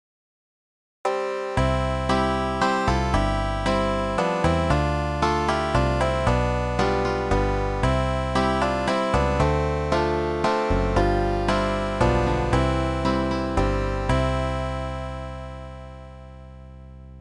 (traditional Welsh harp melody - 3/4 time,
part     - Melodic style)